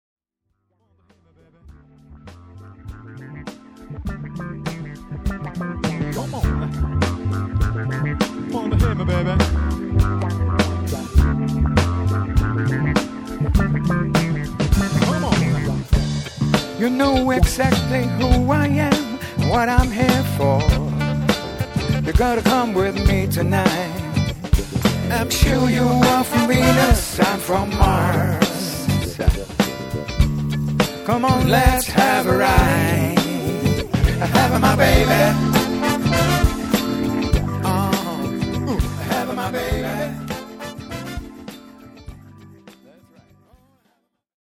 and recorded at home